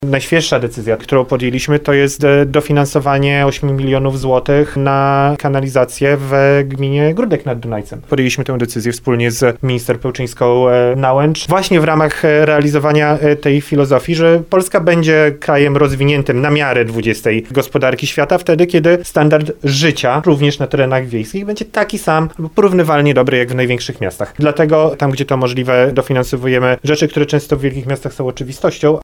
– Pieniądze z KPO przeznaczone dla regionu sądeckiego to już ponad miliard złotych – poinformował wiceminister funduszy i polityki regionalnej Jan Szyszko, który spotkał się z dziennikarzami w Nowym Sączu.